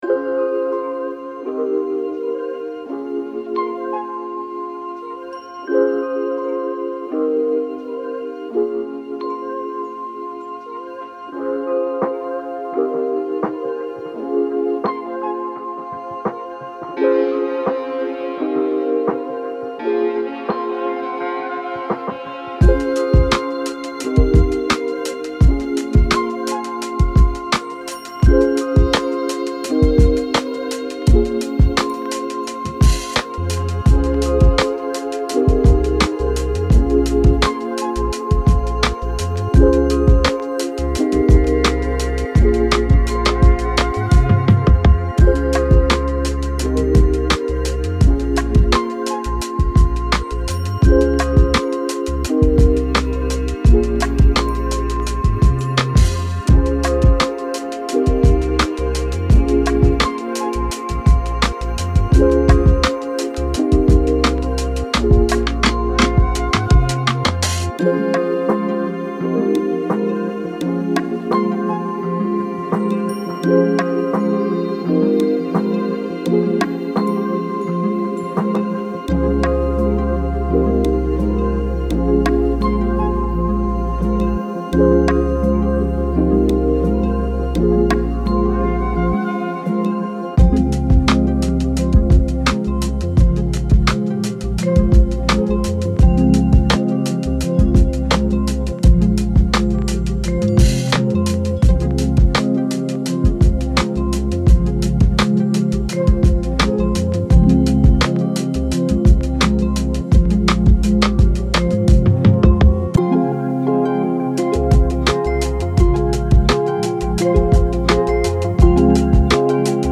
PASSION+-+BPM+85.mp3